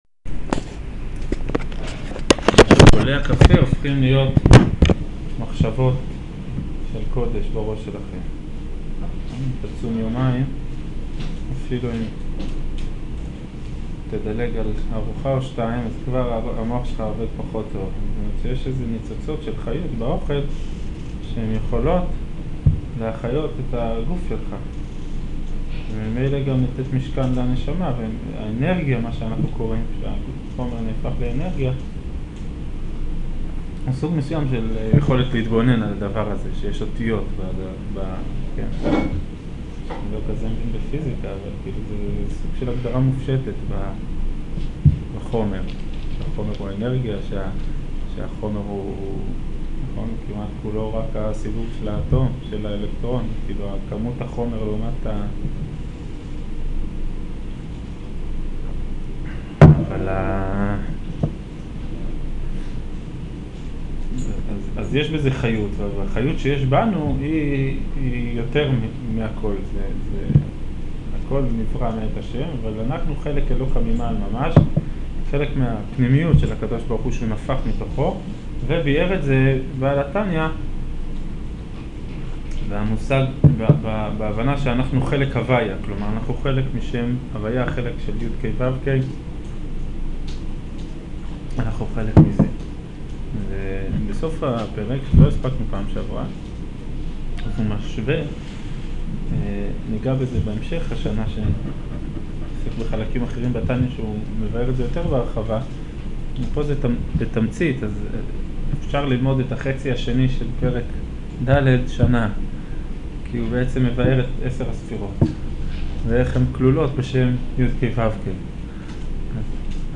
שיעור אגרת התשובה